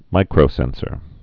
(mīkrō-sĕnsər, -sôr, -krə-)